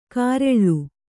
♪ kāreḷḷu